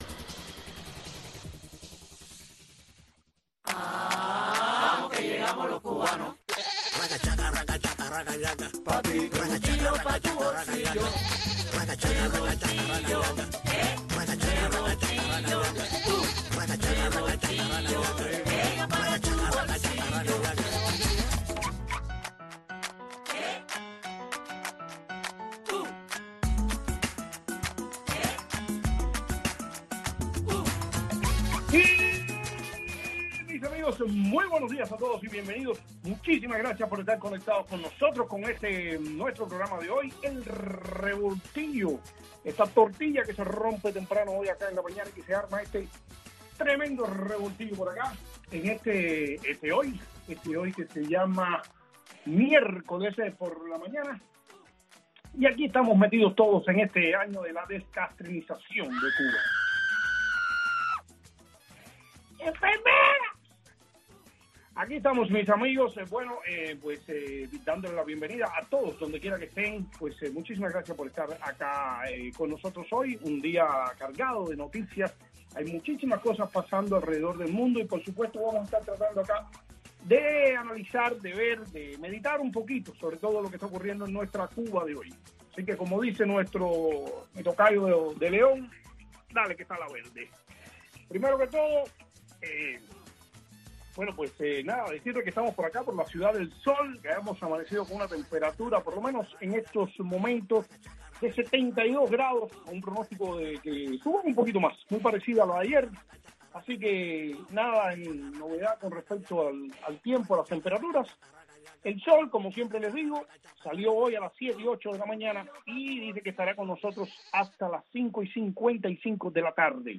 entrevistas, anécdotas y simpáticas ocurrencias.